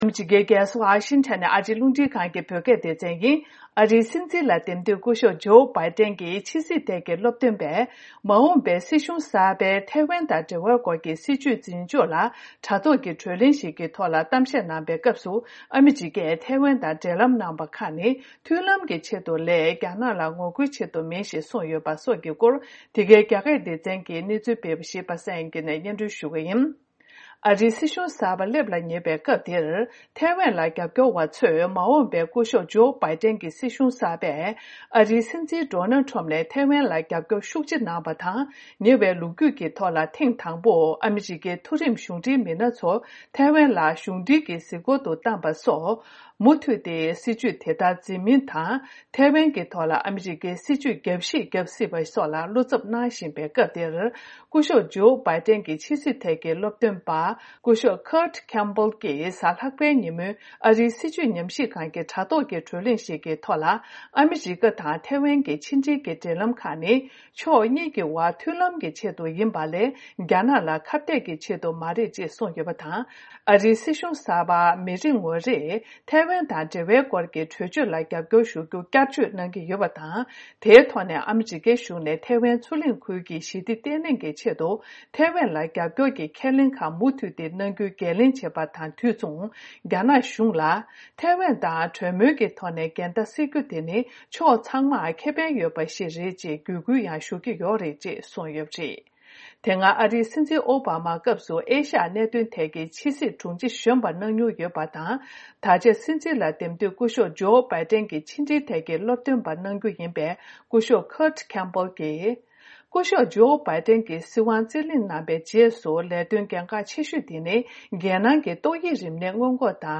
འདི་ག་རླུང་འཕྲིན་ཁང་གི་རྒྱ་སྐད་སྡེ་ཚན་གྱིས་གནས་ཚུལ་སྤེལ་བ་ཞིག་